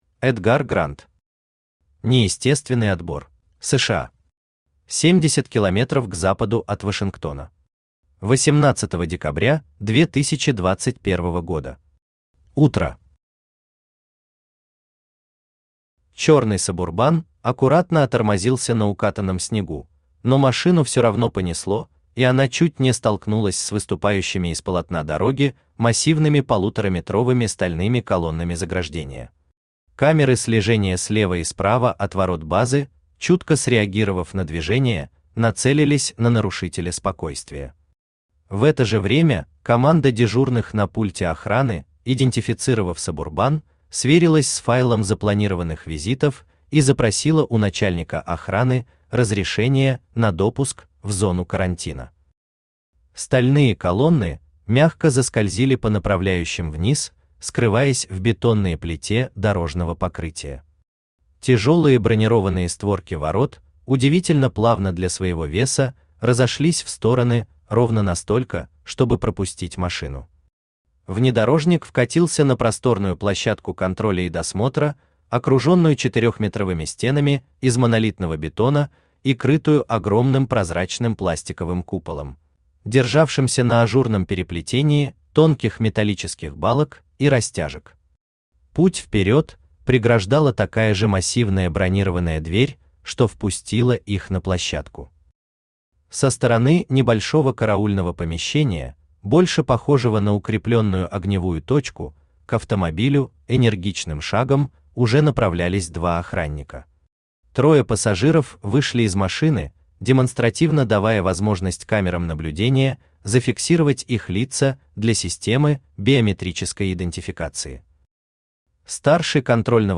Aудиокнига НЕЕСТЕСТВЕННЫЙ ОТБОР Автор Эдгар Грант Читает аудиокнигу Авточтец ЛитРес.